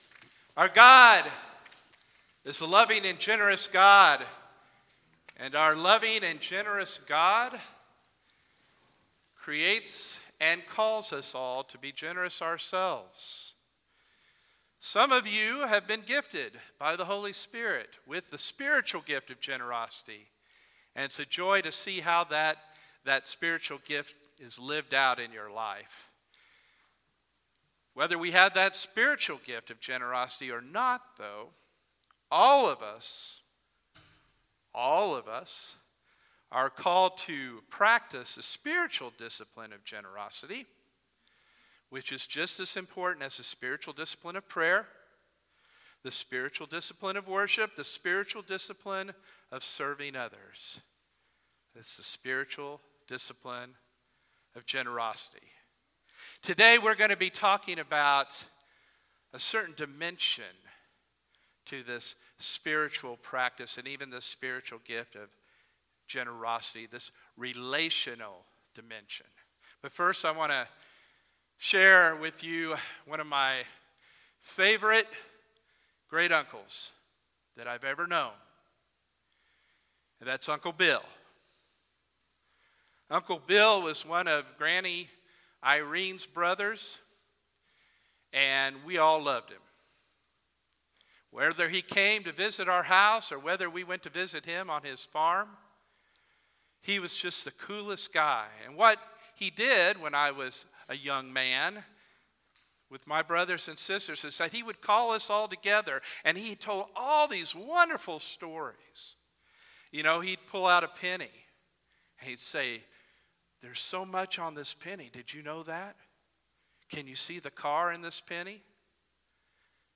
Sermons | Harrisonville United Methodist Church